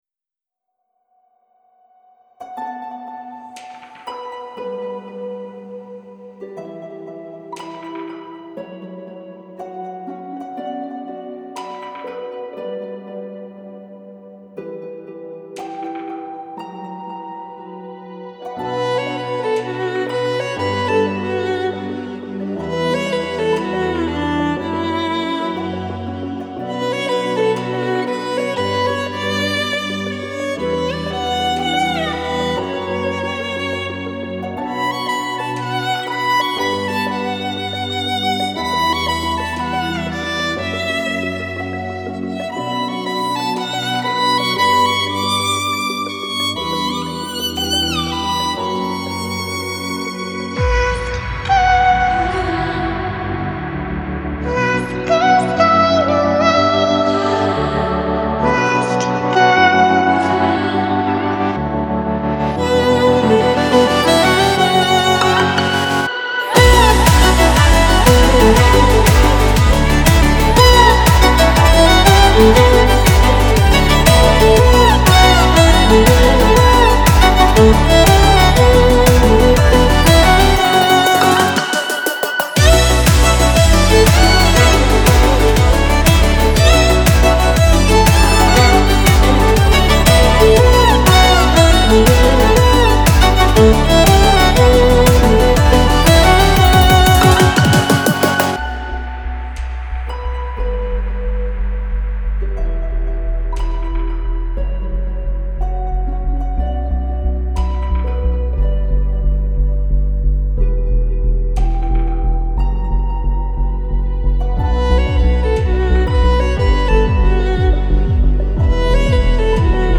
Genre : Classical, Electronic